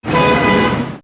carhrn0i.mp3